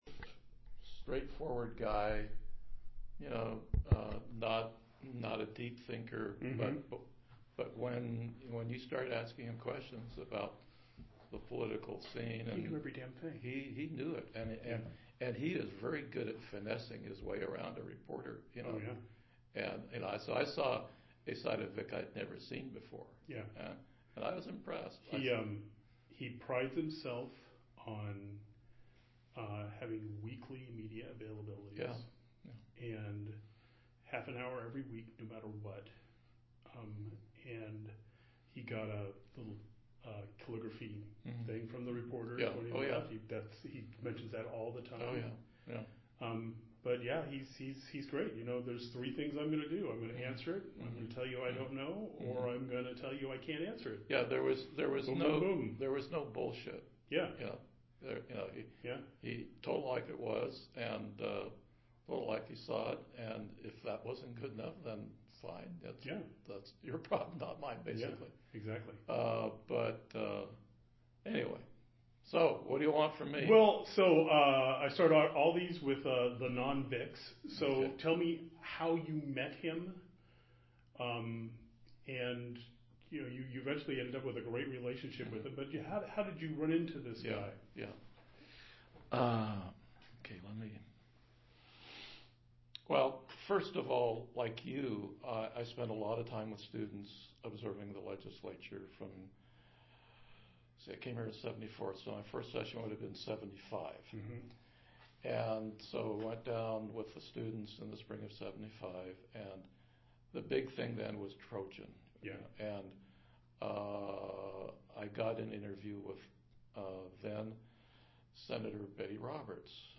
The interview includes discussion of Atiyeh's overall career; Atiyeh's strategic media availability, straightforwardness, and effective legislative relationships; Atiyeh's campaigns and policies, including his abolishment of the Unitary Tax and his reaction to Measure 5; Atiyeh's commitment to public service; his immigrant background as an Arab-American; and the Atiyeh Bros. family business; Atiyeh's leadership style; and Atiyeh's impact on Oregon politics and how he compared to other Oregon governors including McCall, Straub, Goldschmidt, Roberts and Kitzhaber. Note: the interview begins with a short comment that this interview is "part two".